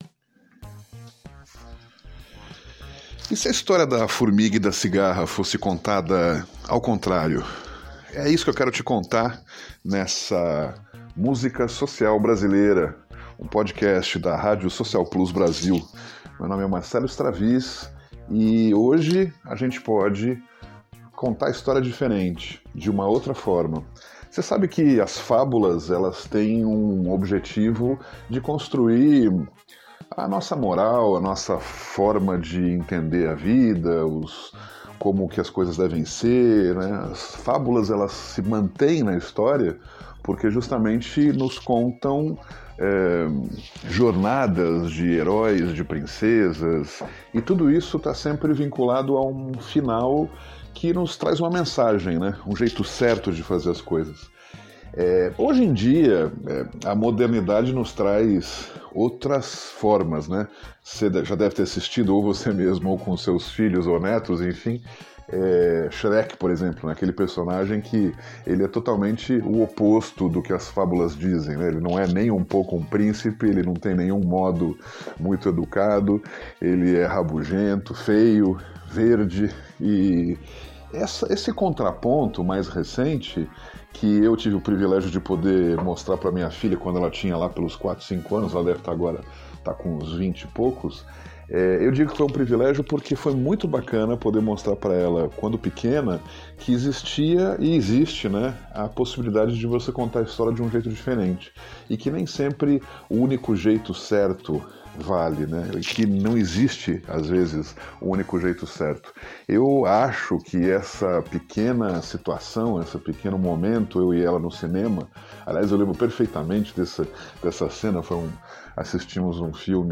Vem ouvir Simone cantando Música Social Brasileira.